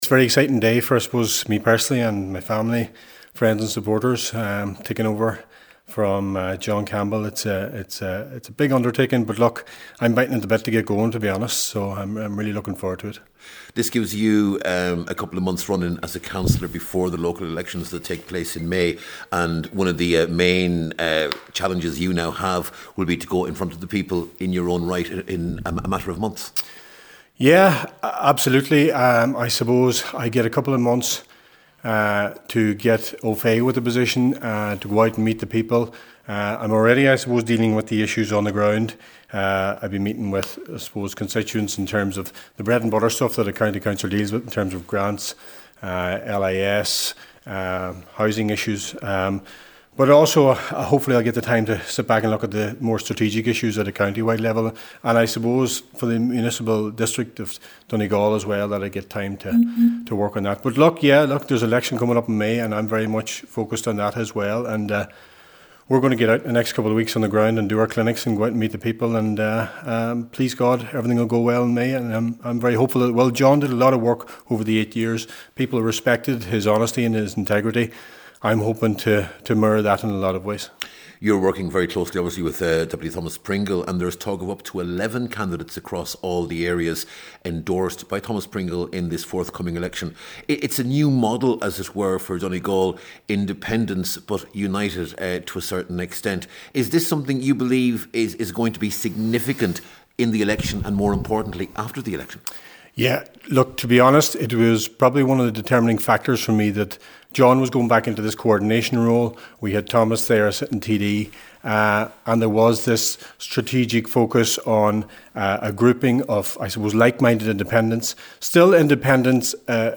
He says he wants to hit the ground running: